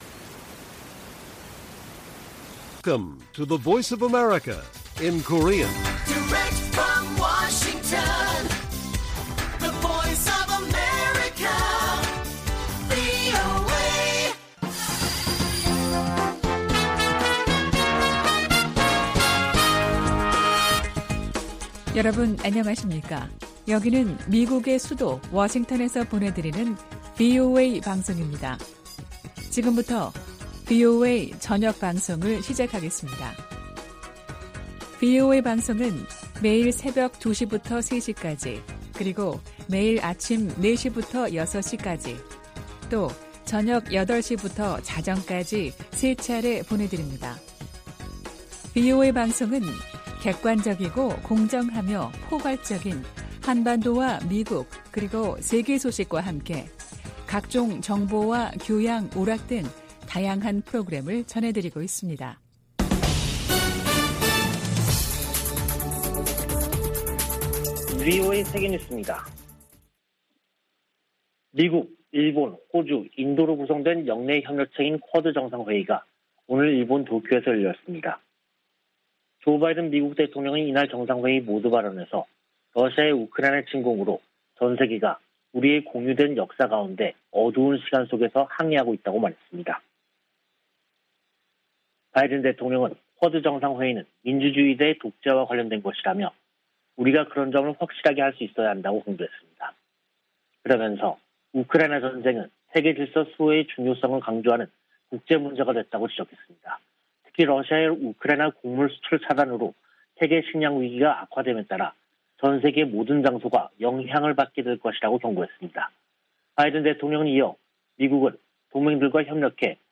VOA 한국어 간판 뉴스 프로그램 '뉴스 투데이', 2022년 5월 24일 1부 방송입니다. 도쿄에서 열린 '쿼드' 정상회의는 한반도의 완전한 비핵화 달성의지를 재확인했습니다. 미국 여야 의원들은 조 바이든 대통령이 방한 중 대규모 투자유치 성과를 냈다며, 대북 최대 압박을 복원해야 한다는 조언을 제시했습니다. 바이든 대통령은 한일 순방으로 다양한 경제, 안보 협력을 추진하면서 중국의 압박을 차단하는 효과를 거두고 있다고 미국 전문가들이 평가했습니다.